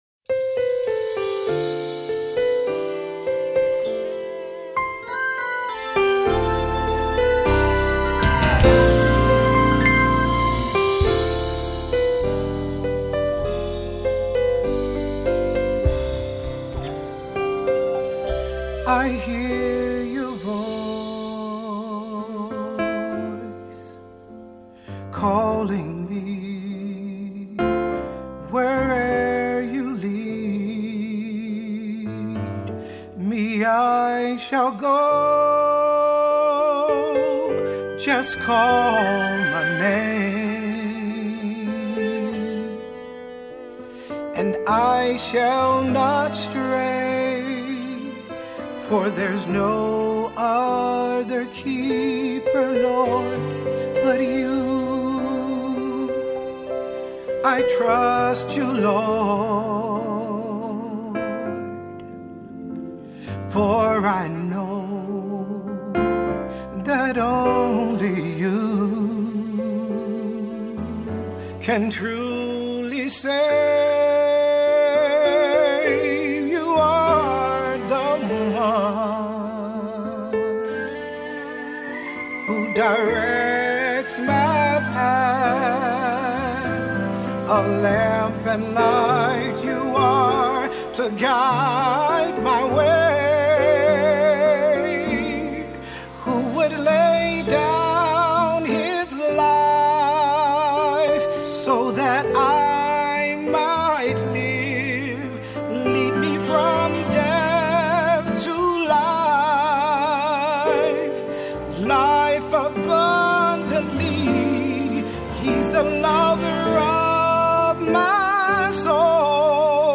Voicing: SAT; Solo; Assembly